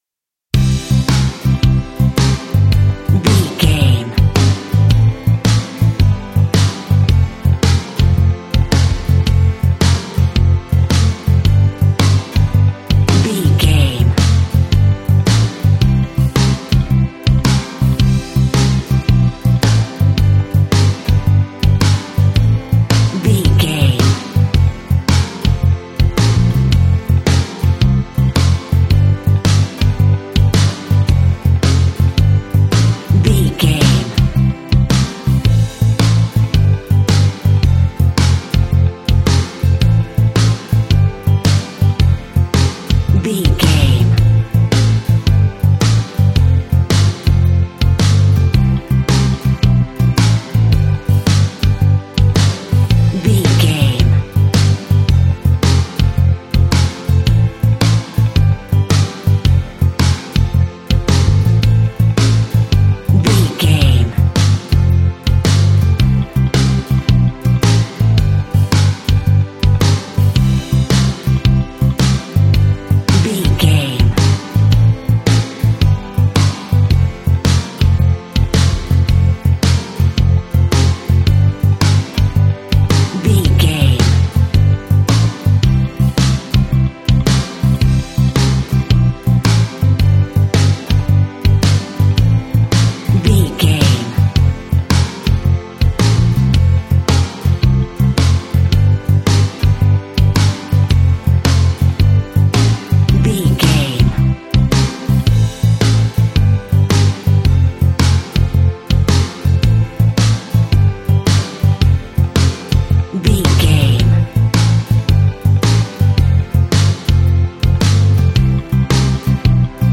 Ionian/Major
calm
happy
energetic
smooth
uplifting
electric guitar
bass guitar
drums
pop rock
instrumentals
organ